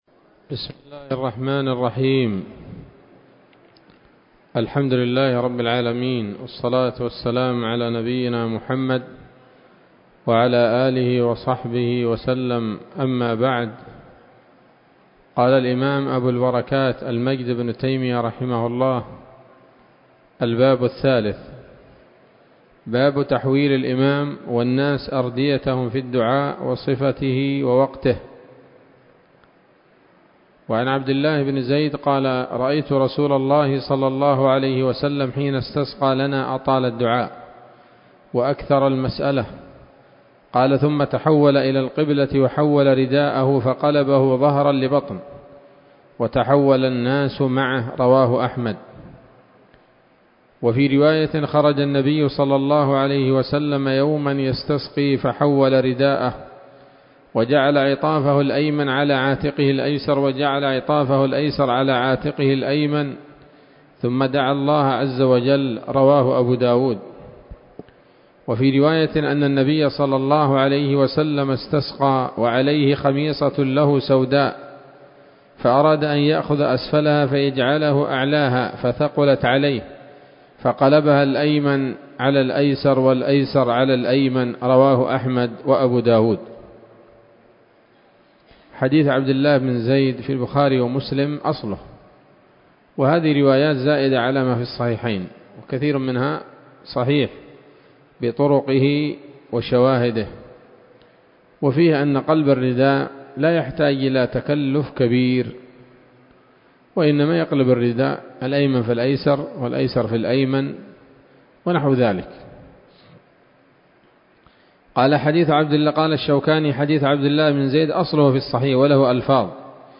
الدرس الخامس وهو الأخير من ‌‌‌‌كتاب الاستسقاء من نيل الأوطار